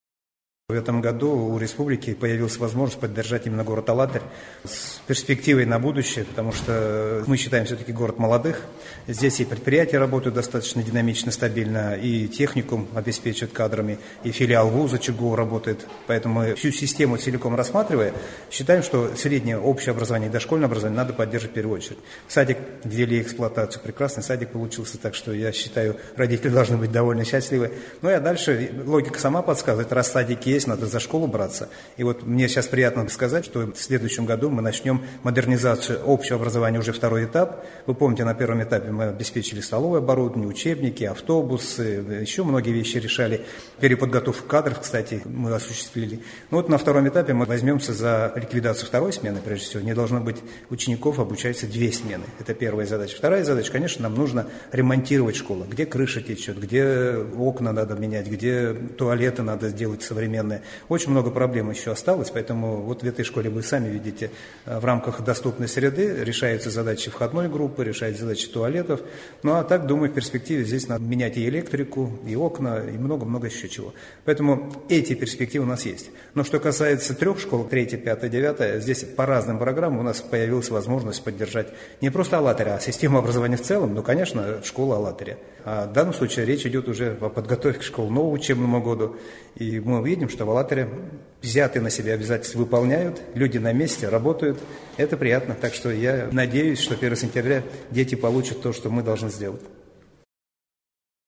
После осмотра школы министр образования и молодёжной политики Чувашской Республики В.Н. Иванов дал интервью местным СМИ.